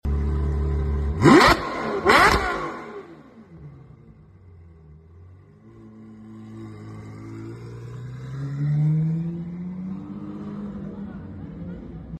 The sound of a straight piped 812